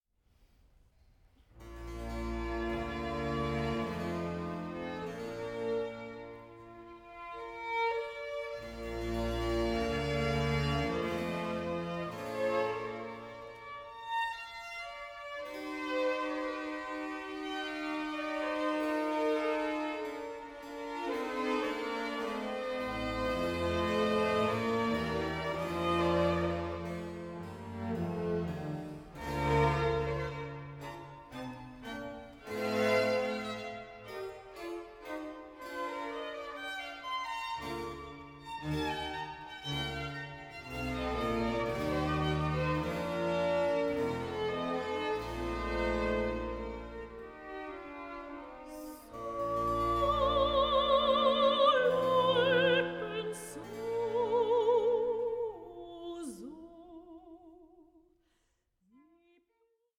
PASSIONATE VOCAL AND SYMPHONIC MUSIC FROM THE CLASSICAL ERA
period-instruments ensembles